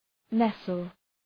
Shkrimi fonetik {‘nesəl}